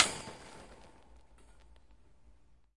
命中 金属 " 命中 金属 笼子01
描述：用木杆击打一块铁丝网。 用Tascam DR40录制。
Tag: 线栅栏 冲击 木材 碰撞 金属的 金属 线级击剑 击剑 木制 打击乐器 护栏 线 冲击